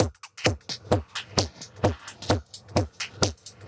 Index of /musicradar/uk-garage-samples/130bpm Lines n Loops/Beats
GA_BeatErevrev130-01.wav